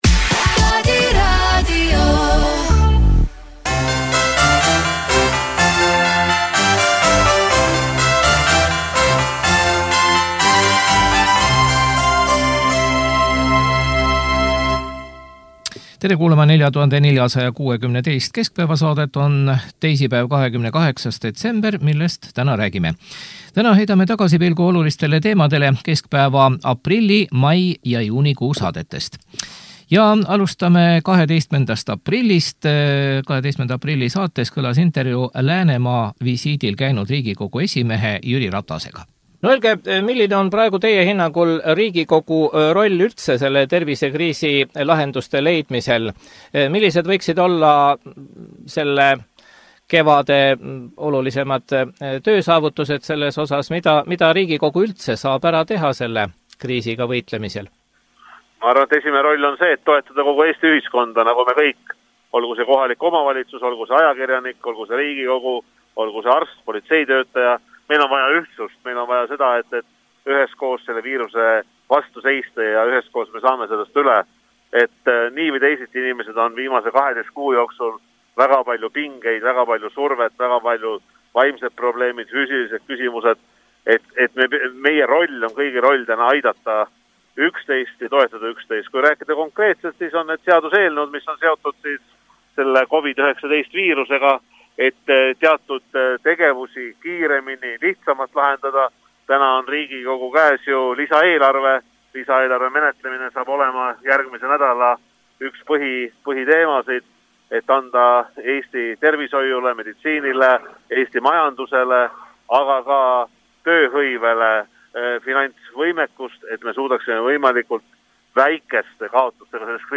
12. aprilli saates kõlas intervjuu Läänemaa visiidil käinud Riigikogu esimehe Jüri Ratasega.